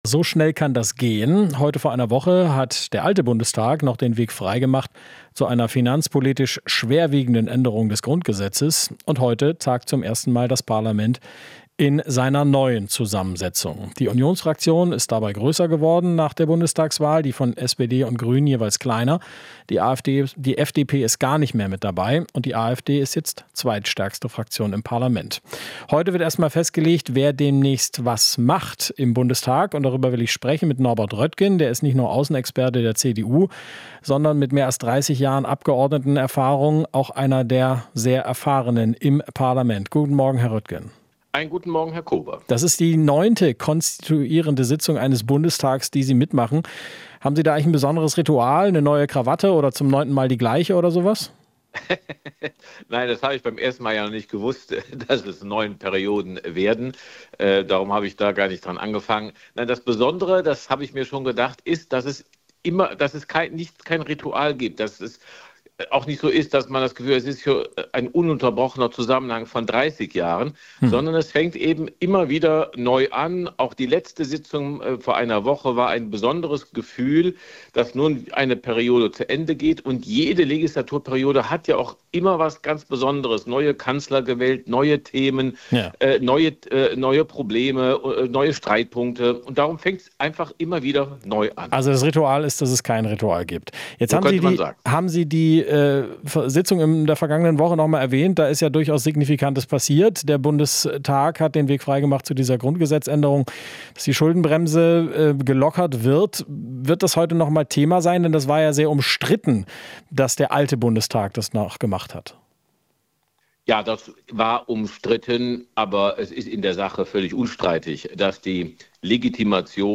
Interview - Röttgen (CDU): AfD hat keinen automatischen Anspruch auf Ämter